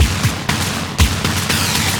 demolition.wav